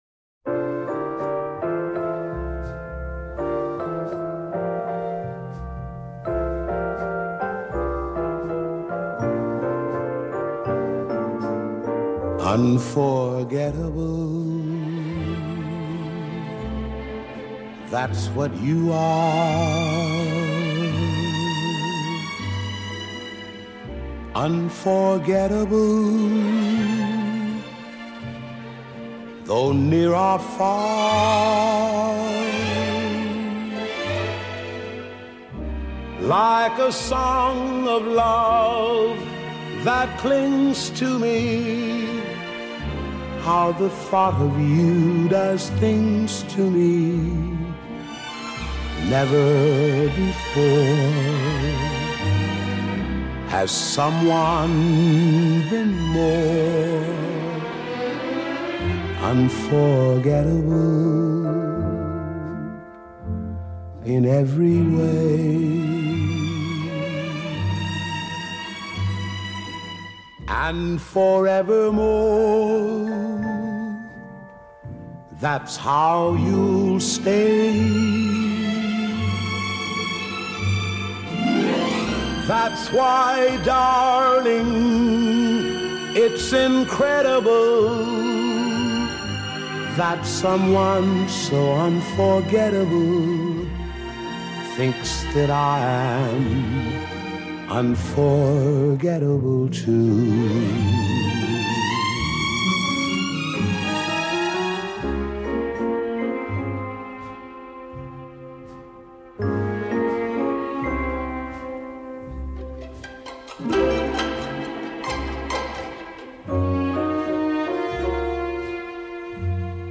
Jazz, Pop, Vocal